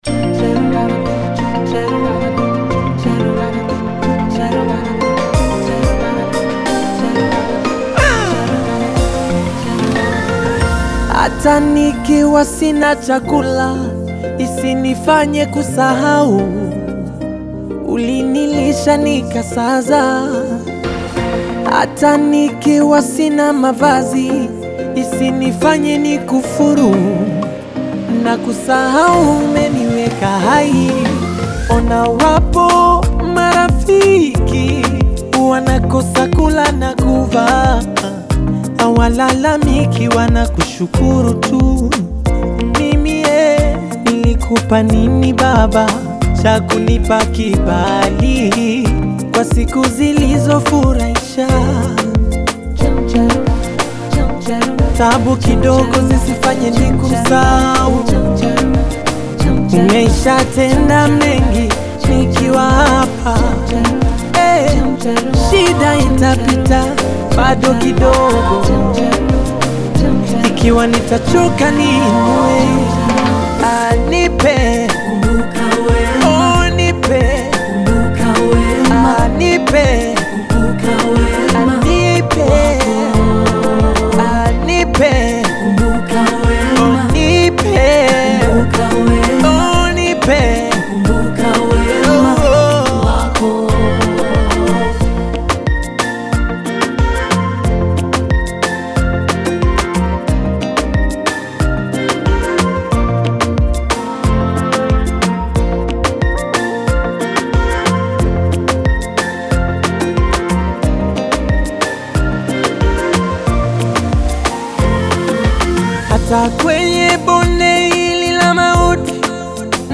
NEW GOSPEL AUDIO SONG